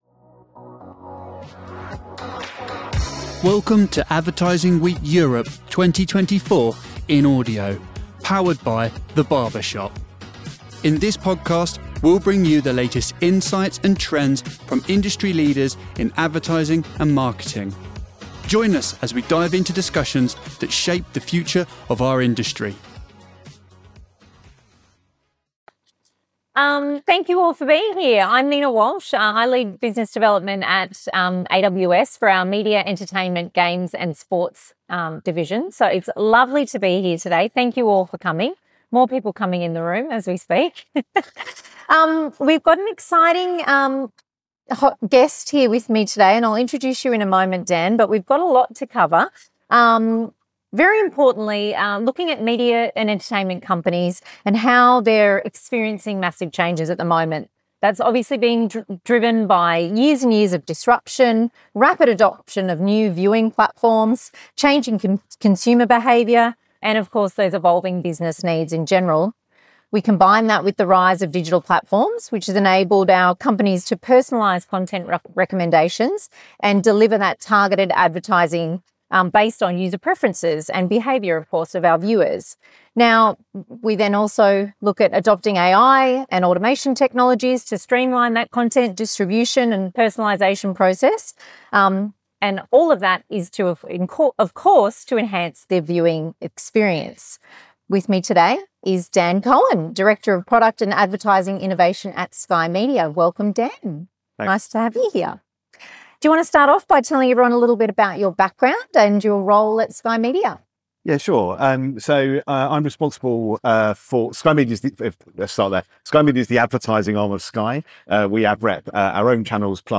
This session provides valuable insights into Sky Media’s innovative approaches to content and audience engagement.